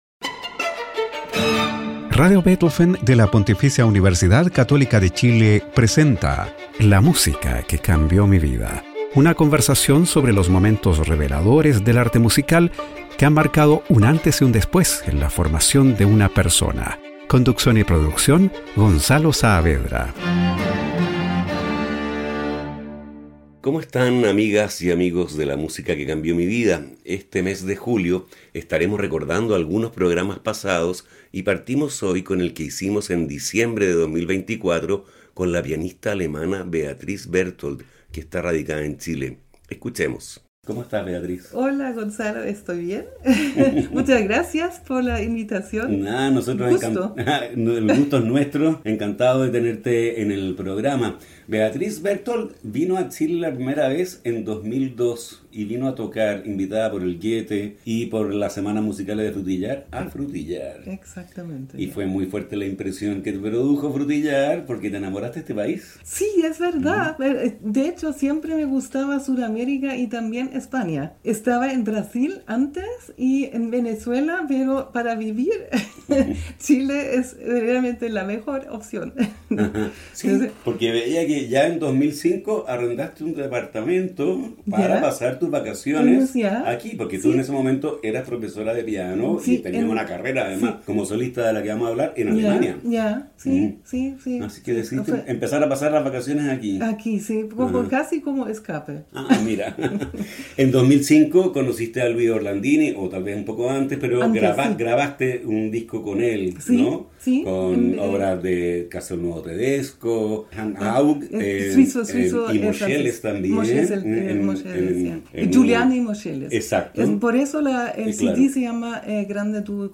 Recordamos la entrevista